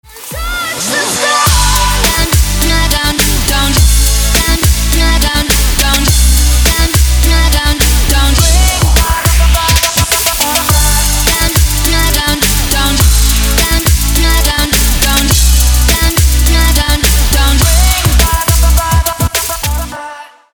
• Качество: 320, Stereo
dance
Trap
future bass